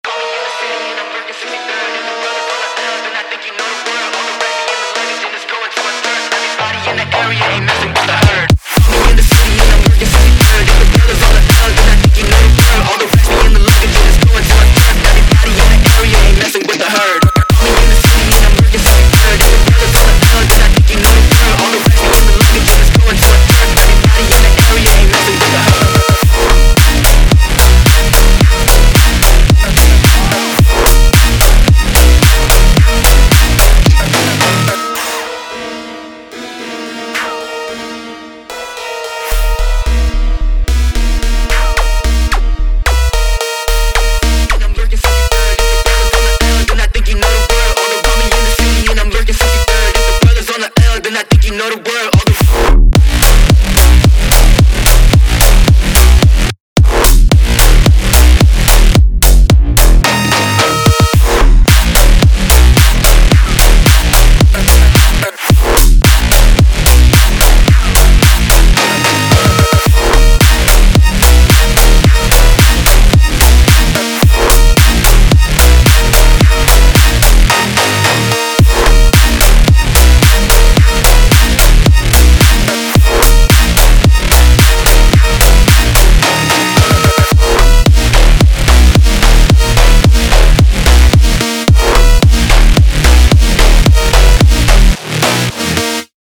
Phonk музыка